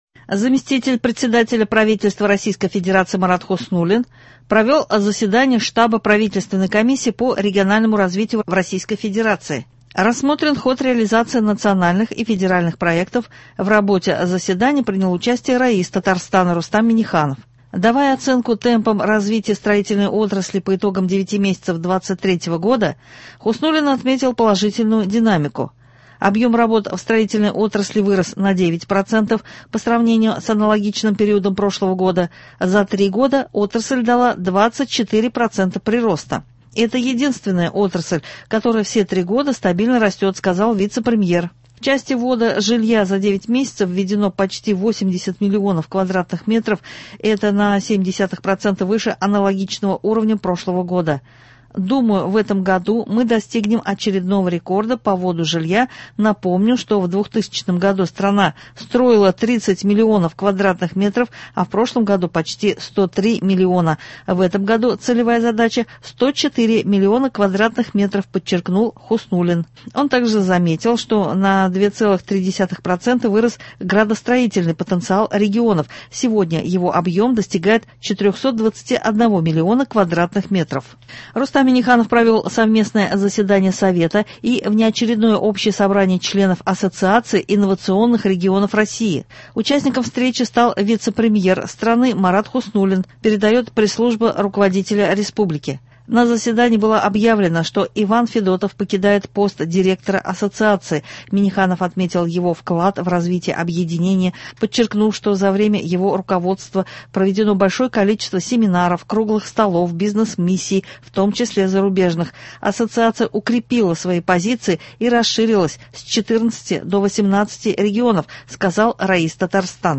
Новости (12.10.23)